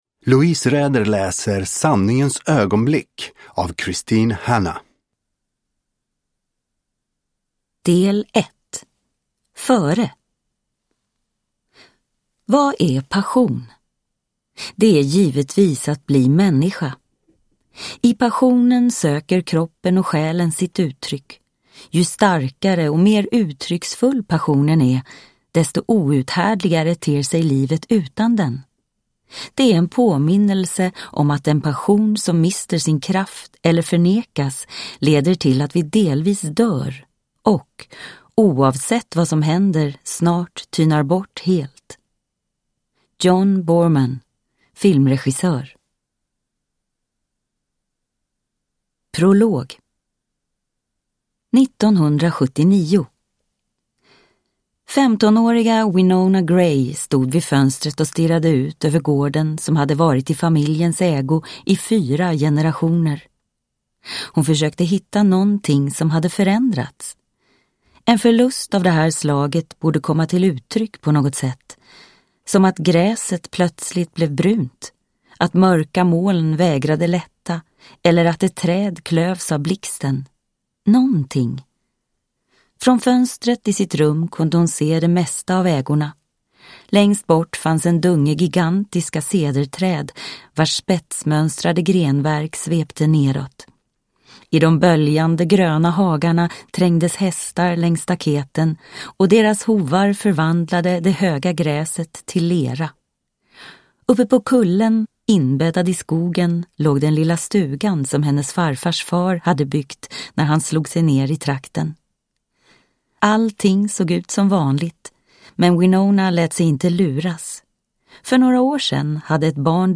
Sanningens ögonblick – Ljudbok – Laddas ner